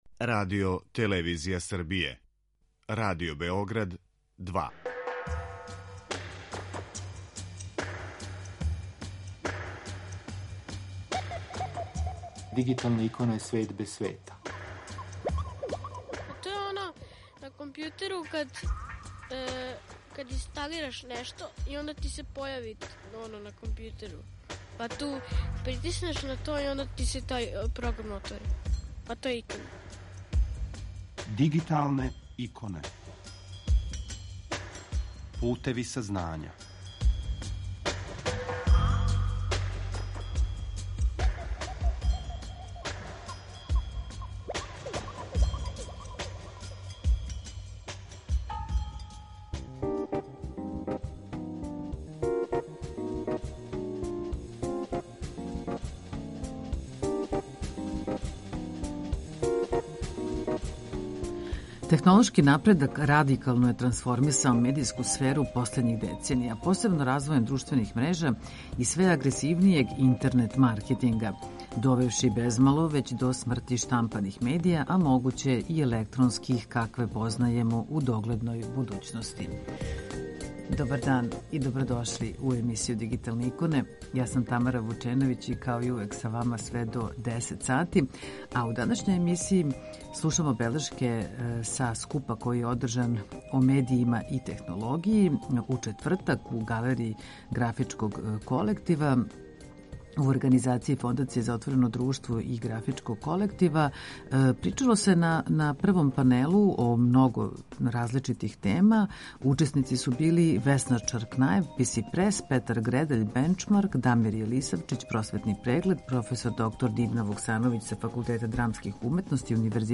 У данашњем издању слушамо одабране снимке са овог догађаја.